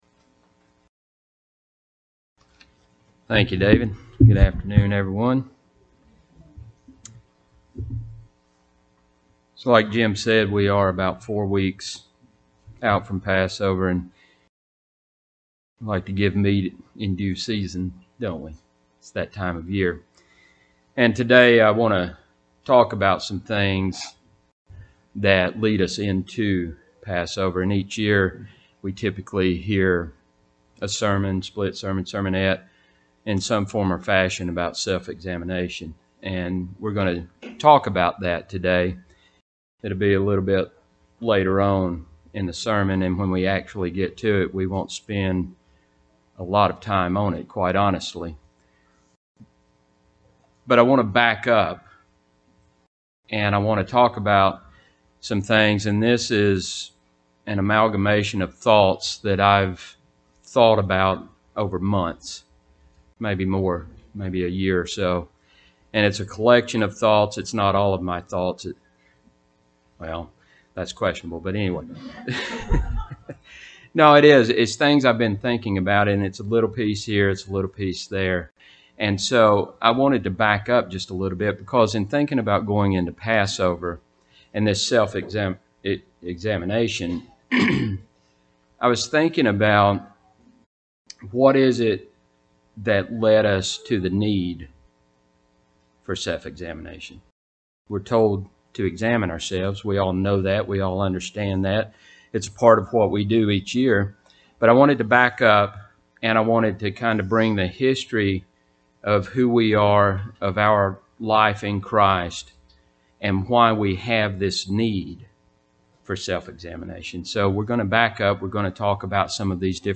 This sermon will look back and ask how did we arrive at this point of needing self examination.
Given in Huntsville, AL